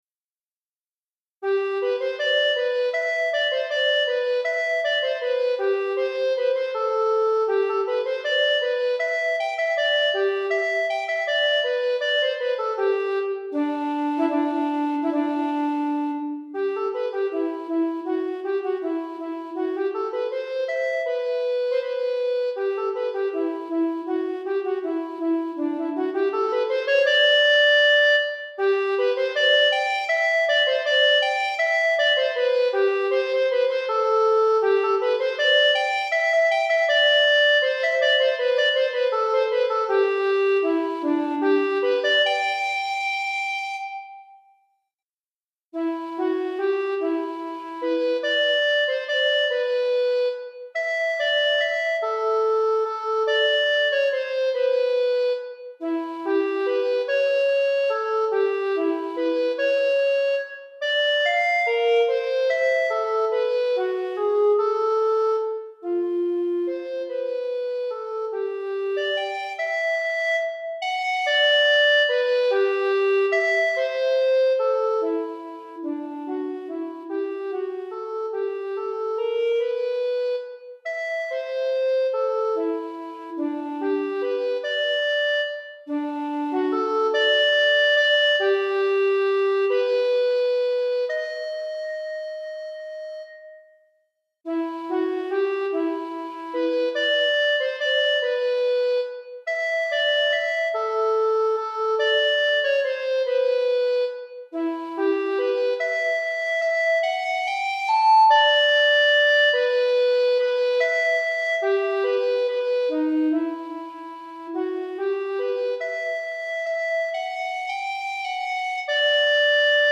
Pour flûte à bec solo DEGRE FIN DE CYCLE 1